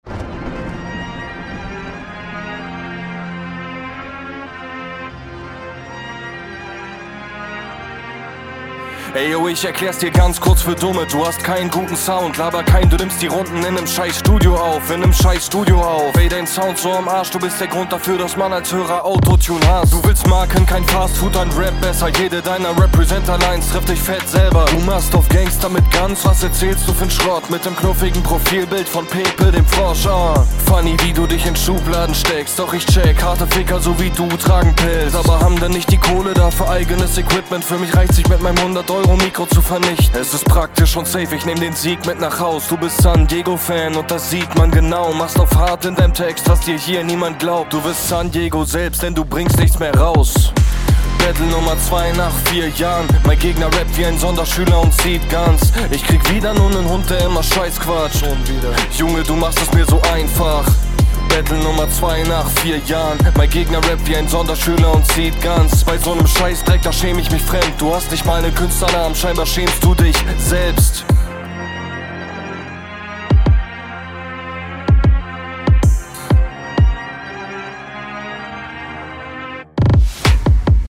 Der Beat ist sehr nice, trifft genau meinen Geschmack.
Sehr starker, routinierter Flow, das wirkt alles enorm effortless.